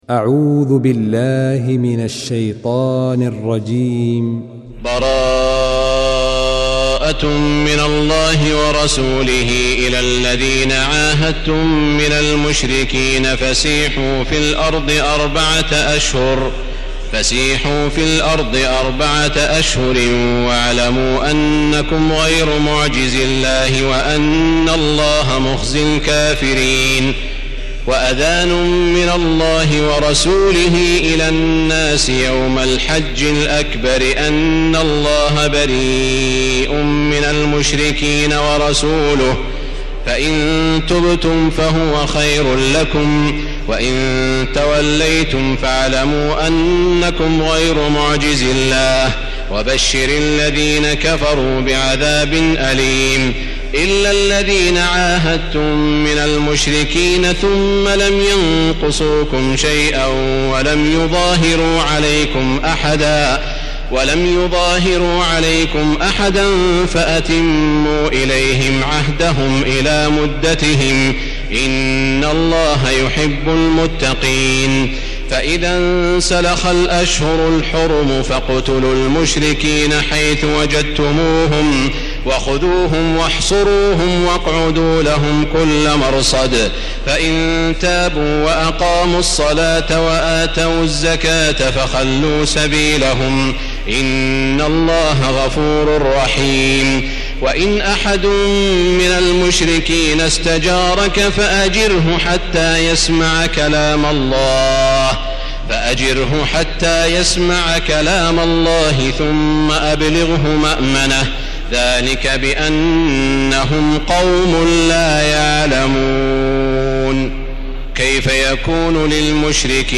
المكان: المسجد الحرام الشيخ: سعود الشريم سعود الشريم معالي الشيخ أ.د. عبدالرحمن بن عبدالعزيز السديس فضيلة الشيخ عبدالله الجهني التوبة